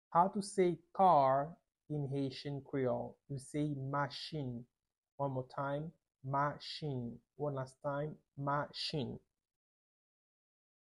Pronunciation:
5.How-to-say-Car-in-Haitian-Creole-–-machin-with-Pronunciation.mp3